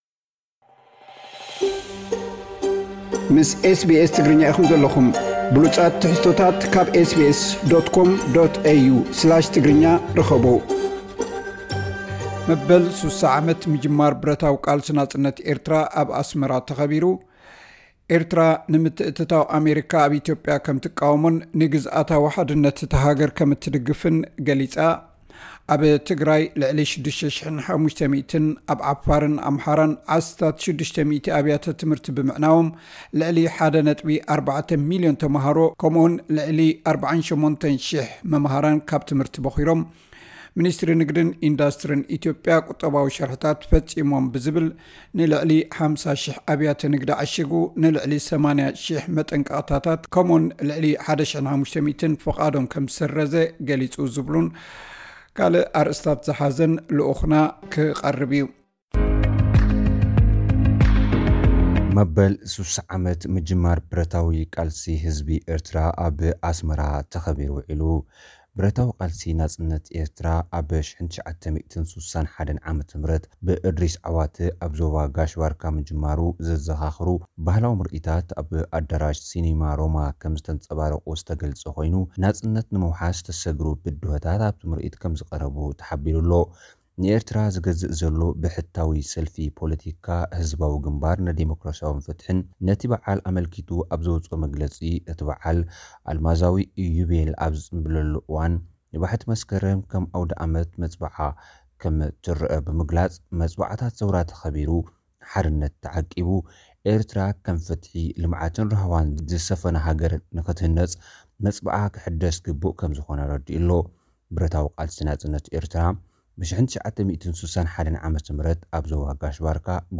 ዝብሉን ካልኦትን ኣርእስታት ዝሓዘ ጸብጻብ ልኡኽና ድሕሪ ዜና ክቐርብ እዩ።